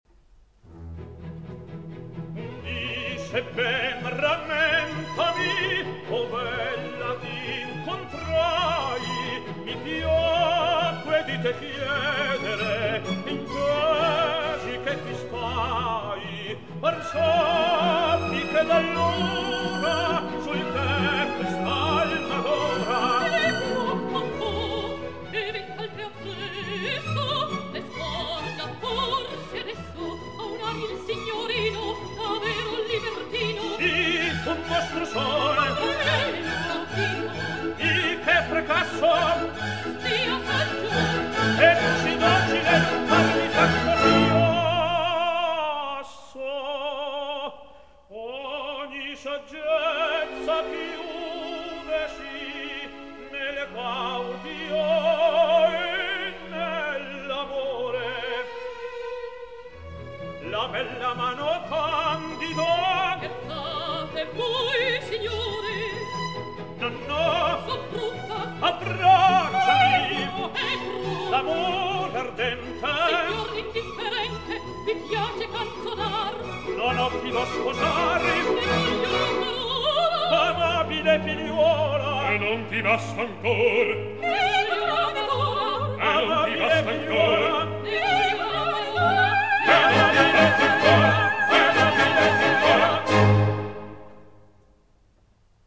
13 - Quartetto Un dì se ben rammentomi.mp3 — Laurea Triennale in Scienze e tecnologie della comunicazione